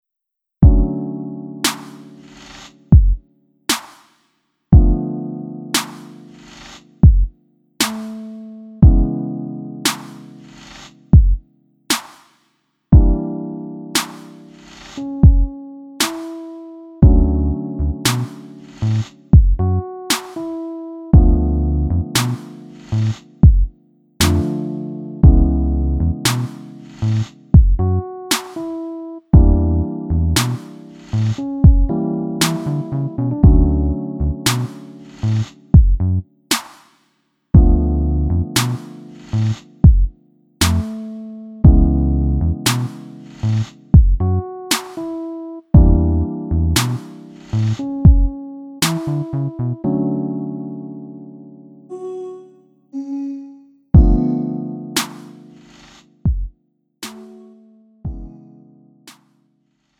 음정 -1키 4:59
장르 구분 Lite MR